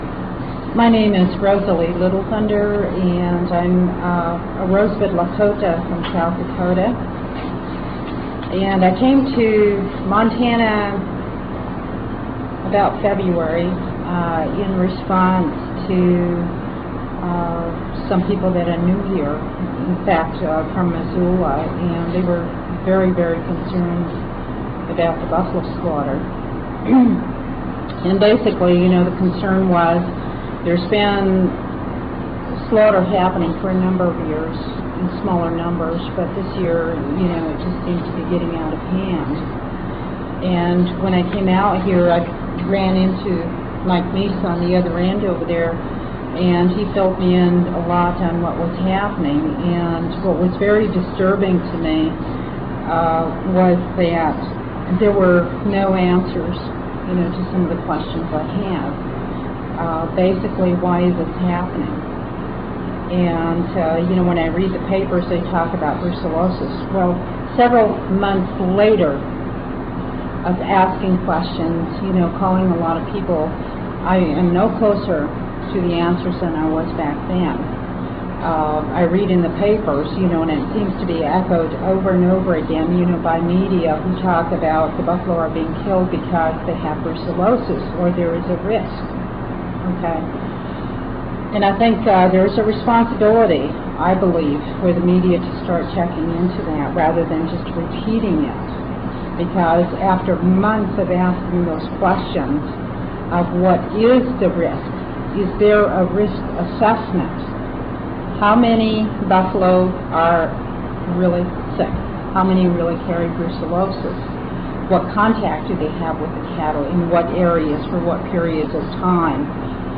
lifeblood: bootlegs: 1997-10-02: honor the earth - missoula, montana (press conference)
03. press conference